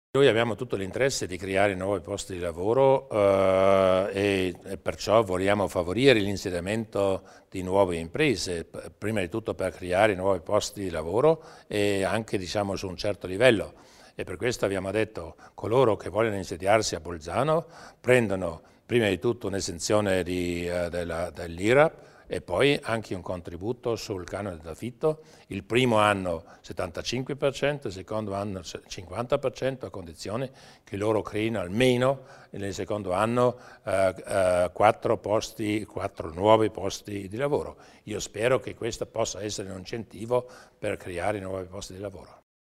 Presidente Durnwalder spiega gli interventi a sostegno dell'economia